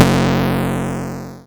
area_effect_b.wav